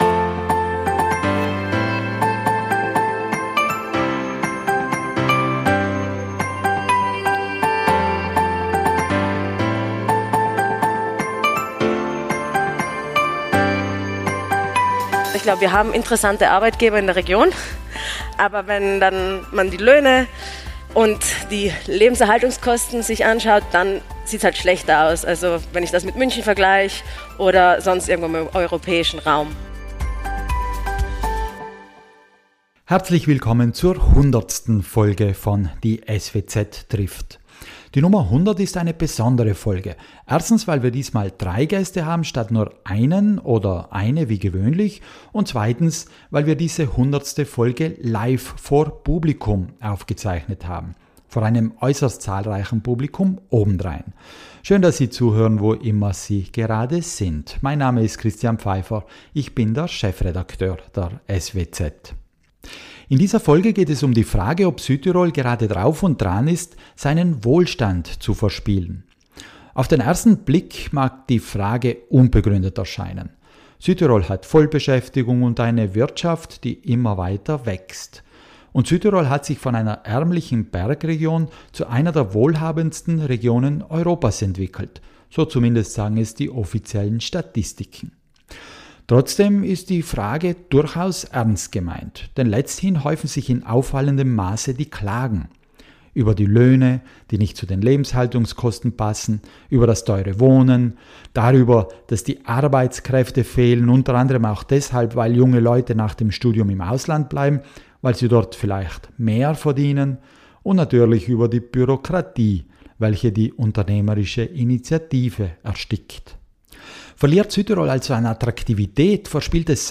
#100 live | Verspielt Südtirol seinen Wohlstand? ~ Die SWZ trifft Podcast